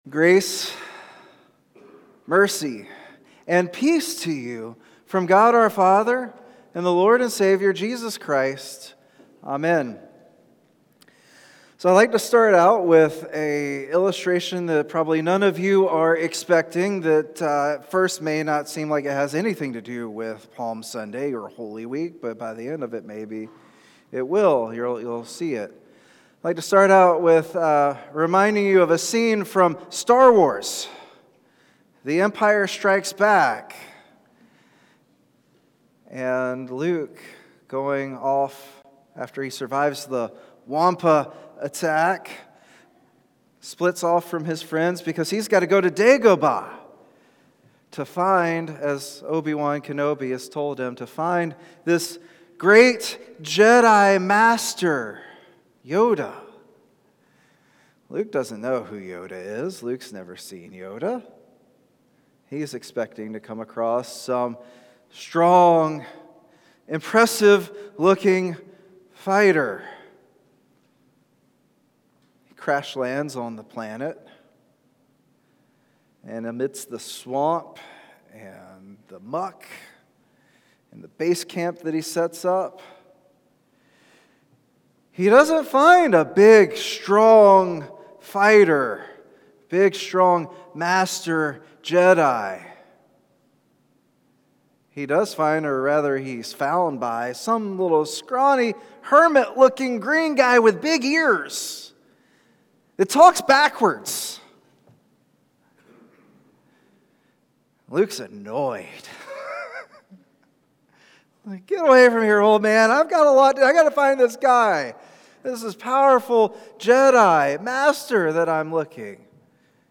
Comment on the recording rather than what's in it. Service Type: Traditional and Blended